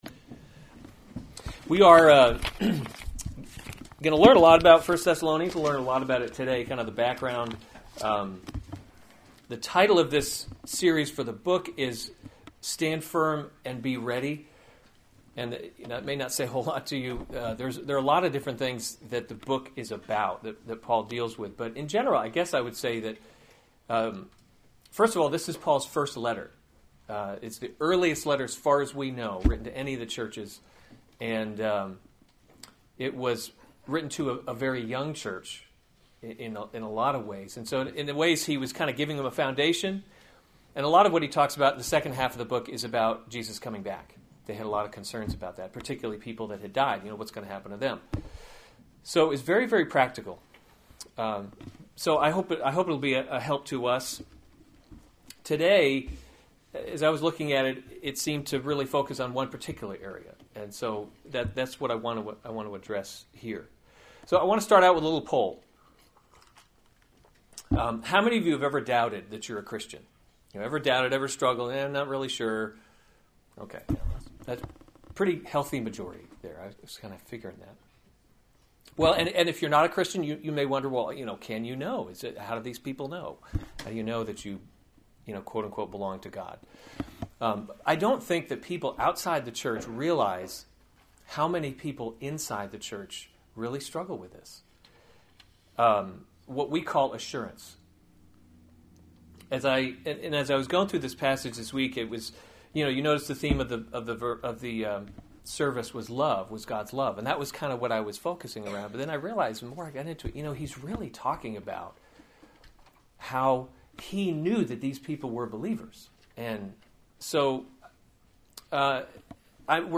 January 9, 2016 1 Thessalonians – Stand Firm…Be Ready series Weekly Sunday Service Save/Download this sermon 1 Thessalonians 1:1-5 Other sermons from 1 Thessalonians Greeting 1:1 Paul, Silvanus, and Timothy, To […]